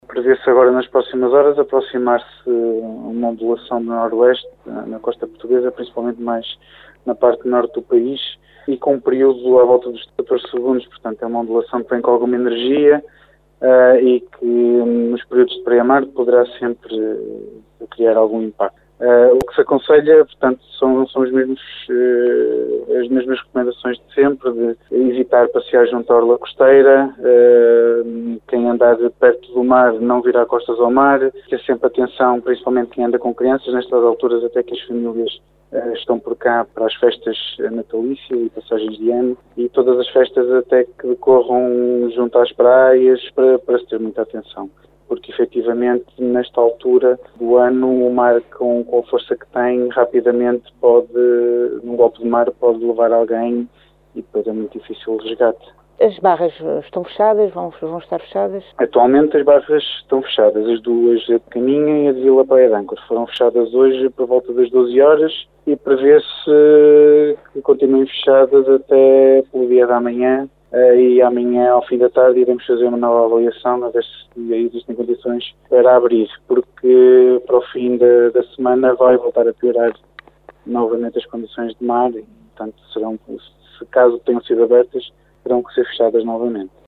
O Comandante do Porto de Caminha Pedro Cervães Costa deixa algumas recomendações.